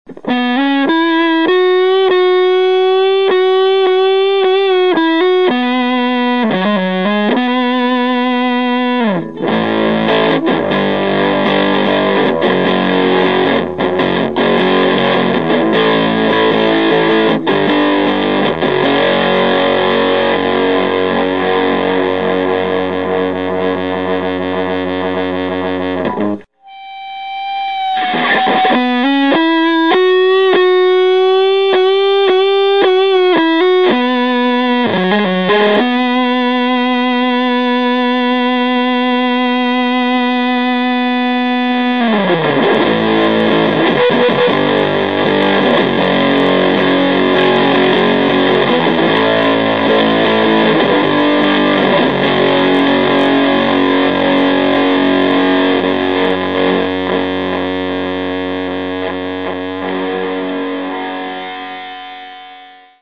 Tonebender Professional MK II clips
The first bit (some single notes, then chords) is with Fuzz set at 50%, the second bit has Fuzz set at 100%.
How the clips were recorded:  Pretty guitar - effect - LM386 amp - 2x12 open cab